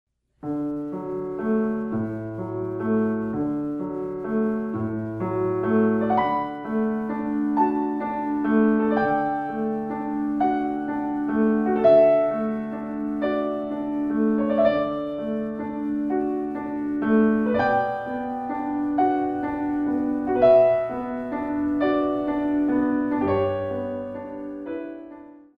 In 3
64 Counts